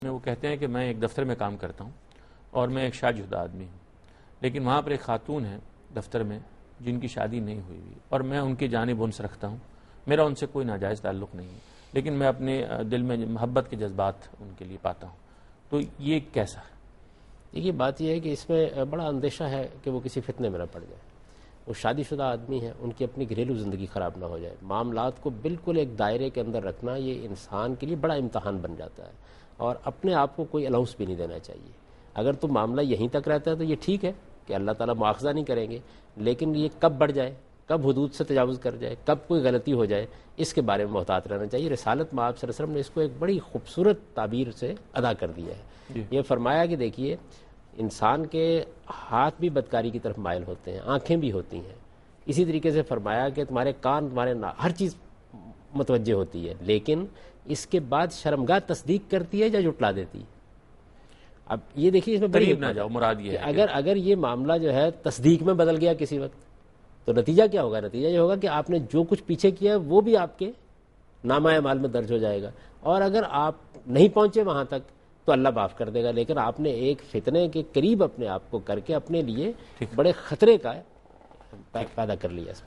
Category: TV Programs / Dunya News / Deen-o-Daanish / Questions_Answers /
دنیا نیوز کے پروگرام دین و دانش میں جاوید احمد غامدی ”خاتون کے لیے محبت کے جذبات“ سے متعلق ایک سوال کا جواب دے رہے ہیں